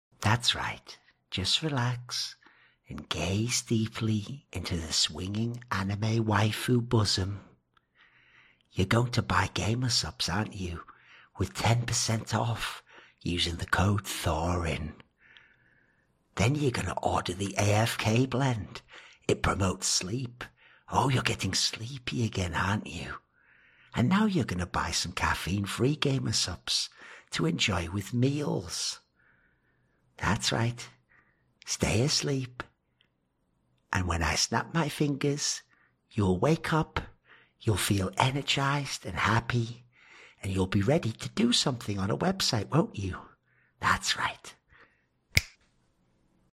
You will buy Gamersupps' AFK - Roleplay [ASMR Intentional]